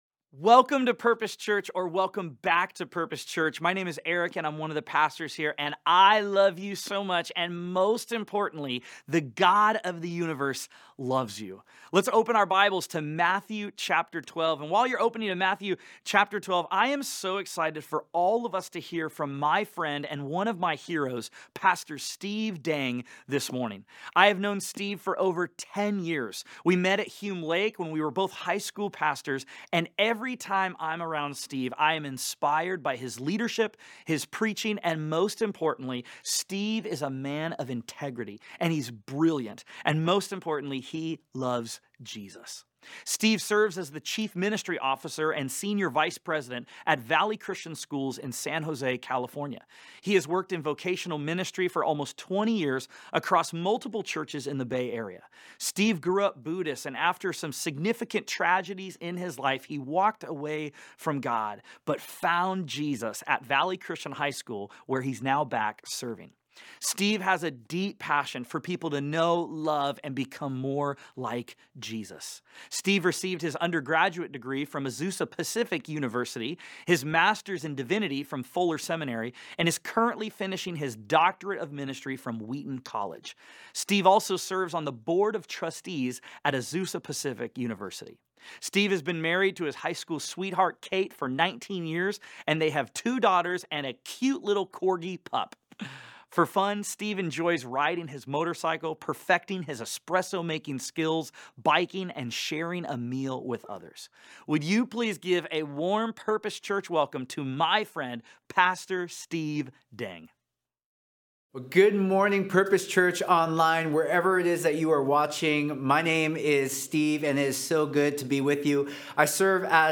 In Matthew 12, Jesus is confronted by religious leaders who have lost sight of God’s design for Sabbath which is about rest and relationship and turned it into ritual. In this week’s sermon we look at the myths about rest and how we can live out a sustaining relationship with God in our busy lives.